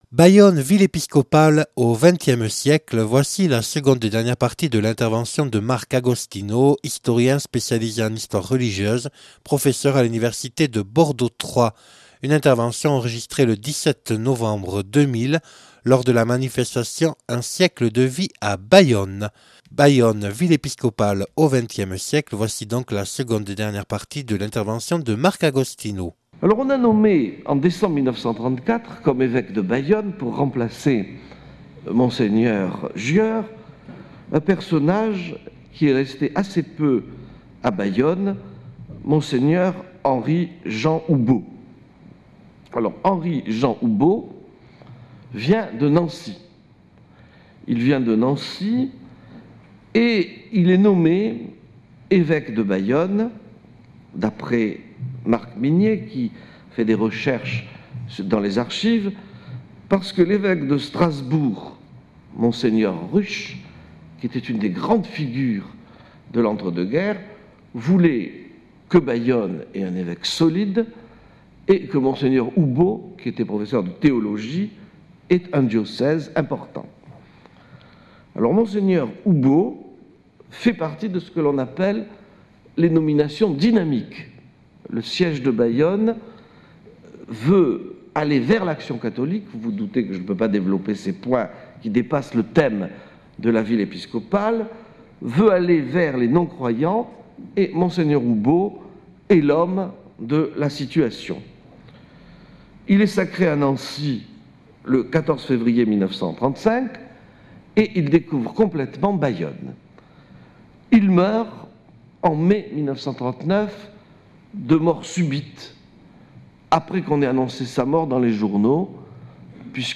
(Enregistrée le 17/11/2000 à la Mairie de Bayonne).